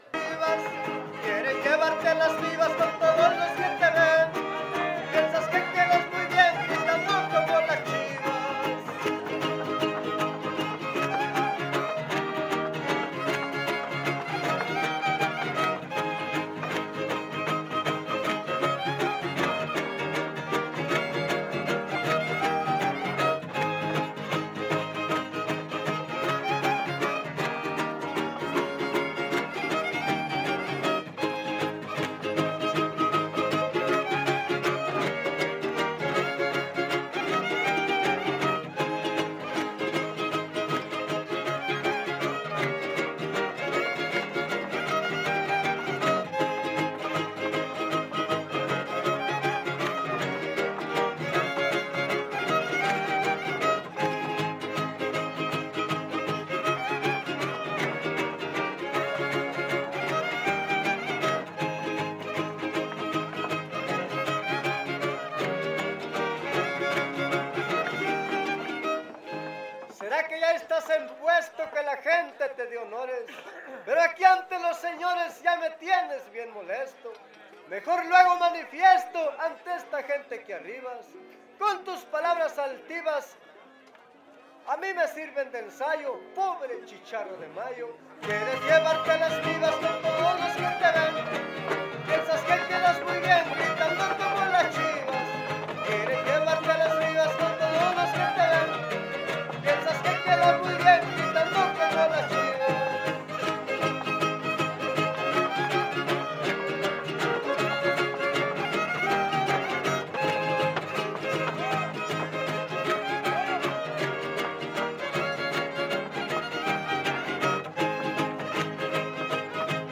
Huapango arribeño
Location Cárdenas, San Luis Potosí, Mexico
No identificado (violín segundo)
Vihuela Guitarra Violín